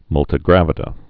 (mŭltĭ-grăvĭ-də)